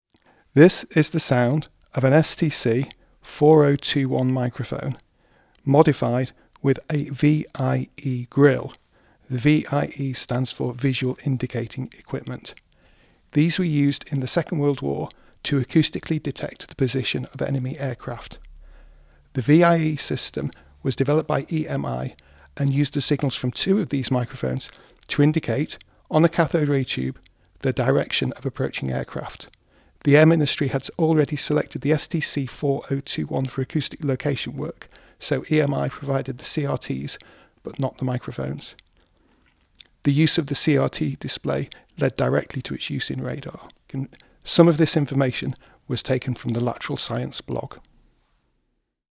STC 4021c dynamic microphone with super rare VIE grill.
Good working order – nice sounding example.
Here is a sound clip of this microphone….